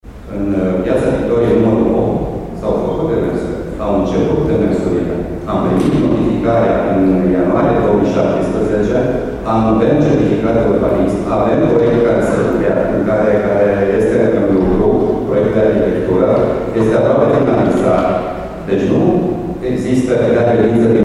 Un avocat a explicat că după notificarea de la primărie asociaţia pe care o reprezintă a început demersurile pentru autorizare.